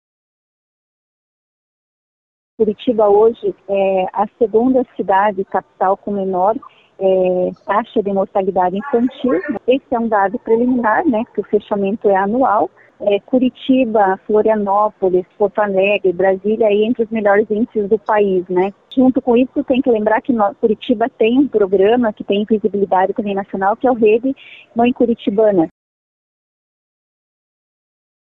Durante a prestação de contas, a secretária Tatiane Filipak contou que a meta é reduzir ainda mais o número de mortes, com foco em acompanhar as gestantes com visitas domiciliares e até uso de aplicativos de comunicação.